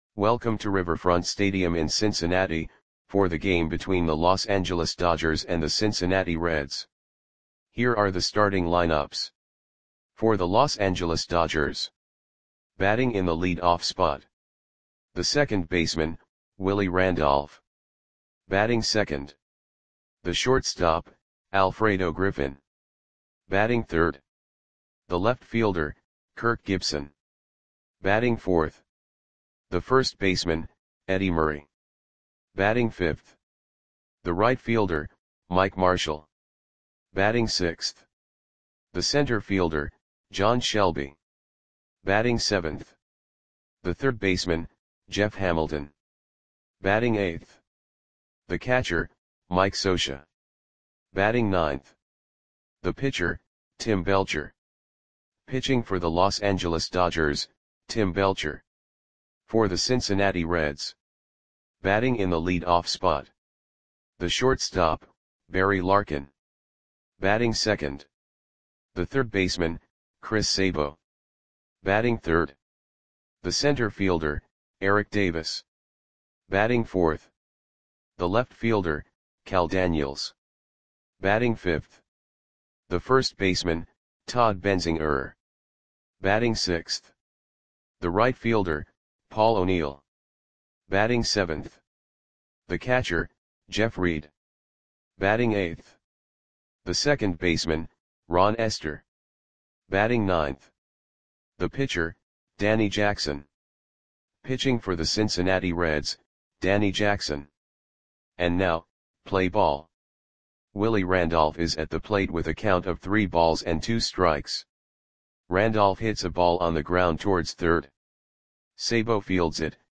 Audio Play-by-Play for Cincinnati Reds on April 3, 1989
Click the button below to listen to the audio play-by-play.